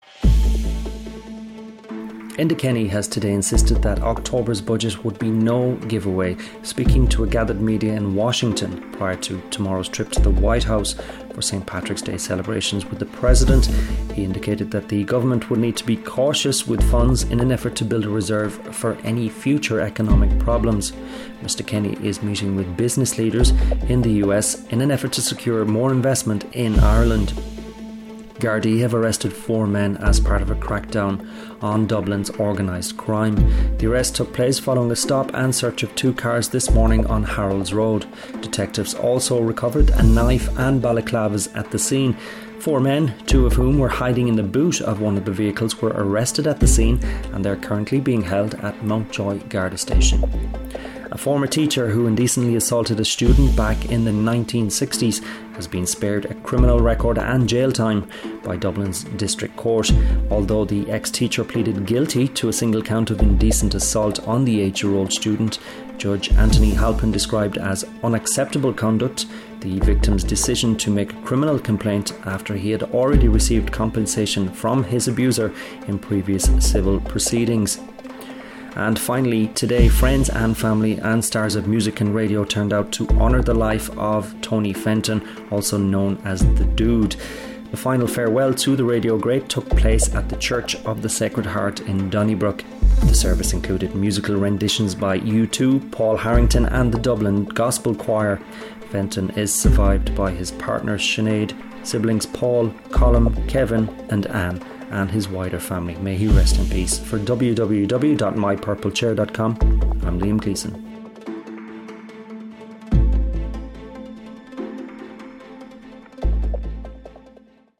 News Briefs March 16th 2015